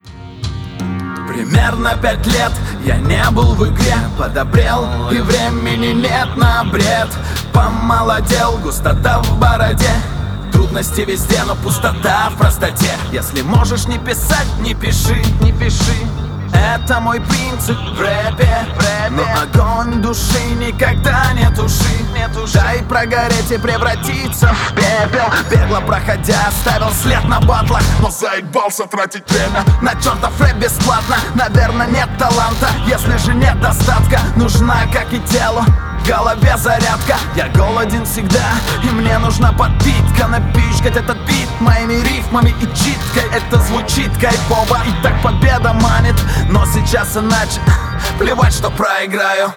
Текст обычный, читка хороша, но строки совсем пустые, скучновато слушать